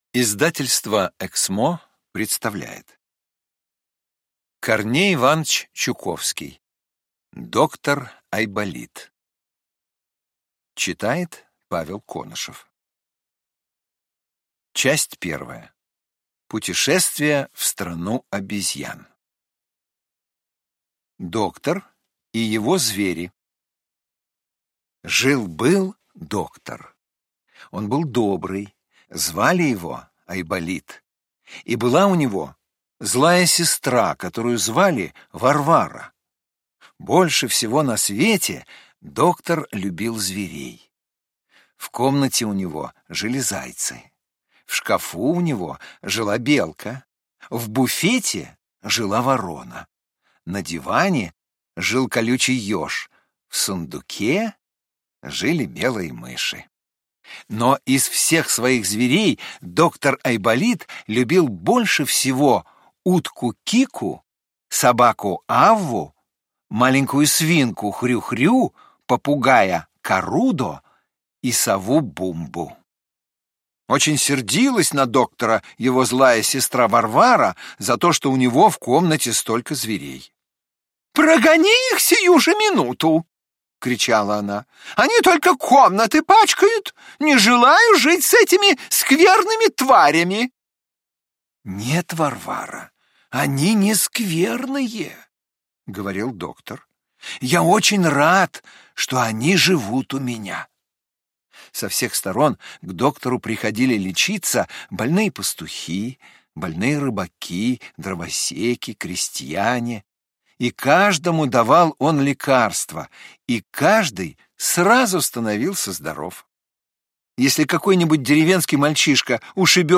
Аудиокнига Доктор Айболит | Библиотека аудиокниг